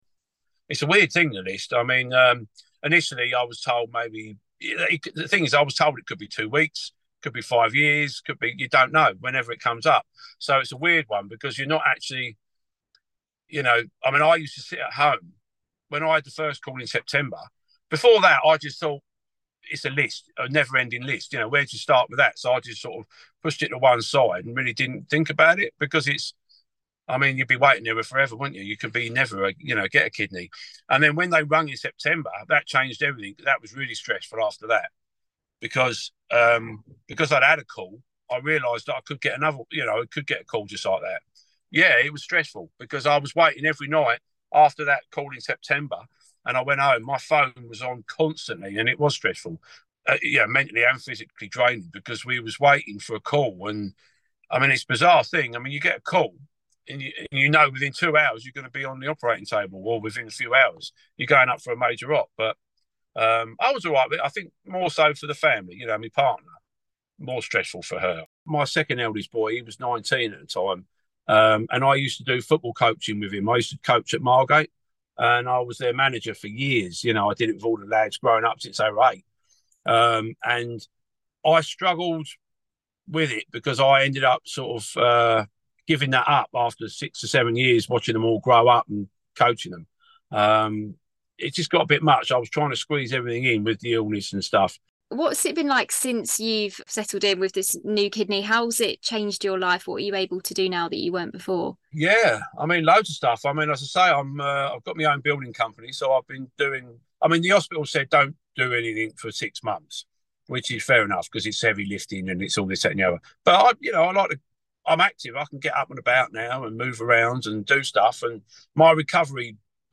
A Kent dad has been telling us how a kidney transplant changed his life, as we mark Organ Donation Week.